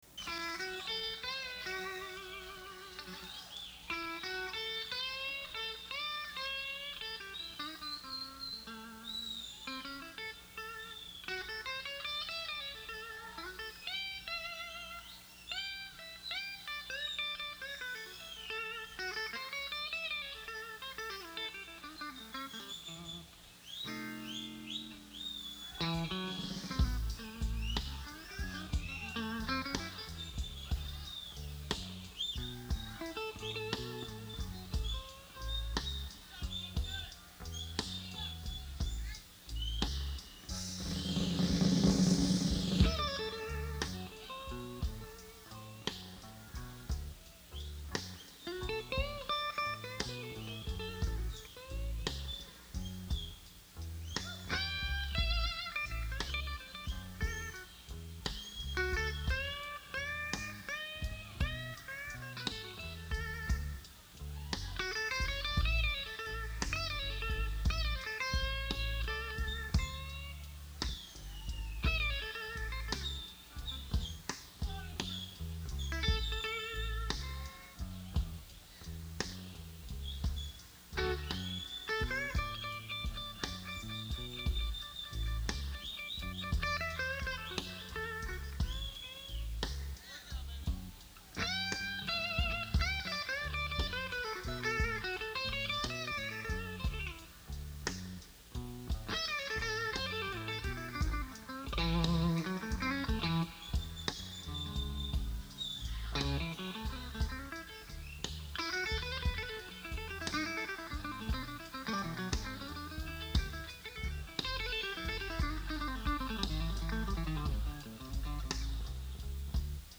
Houston, TX